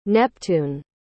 Planeta Tradução em Inglês Pronúncia